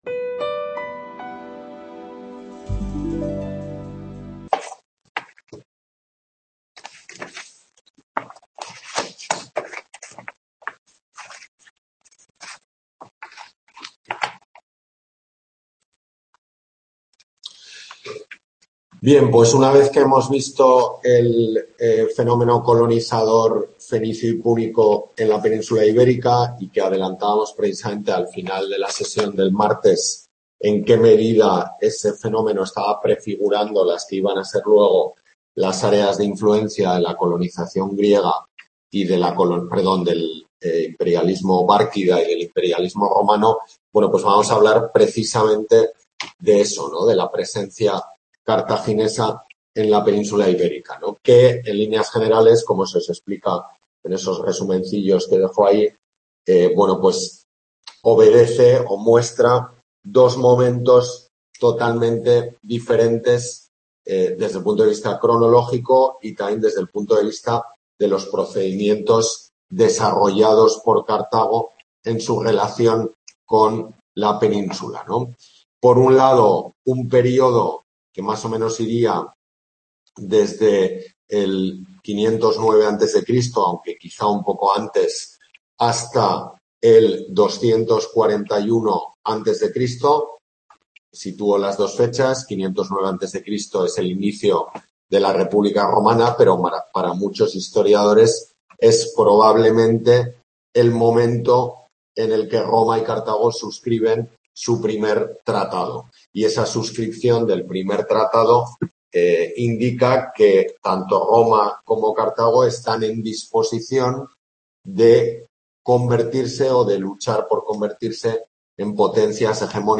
Tutoría de Historia Antigua de la Península Ibérica en la UNED de Tudela